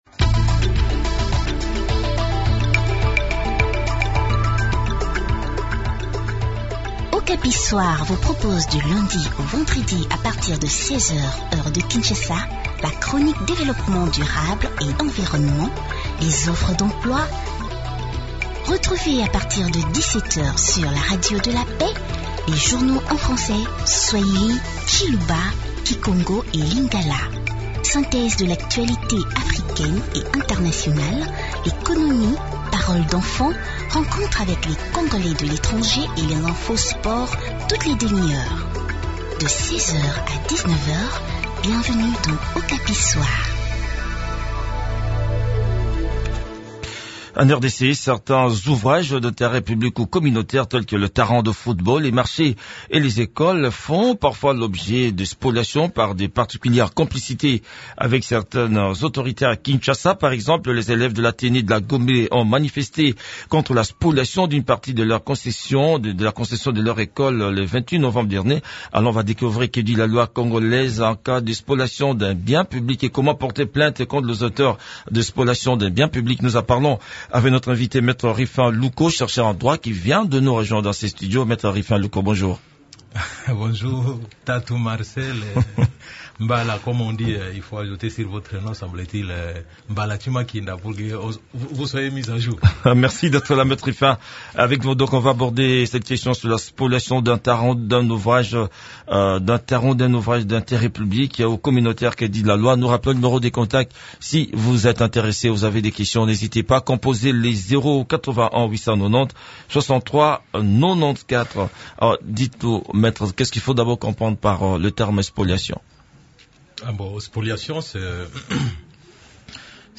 chercheur en droit.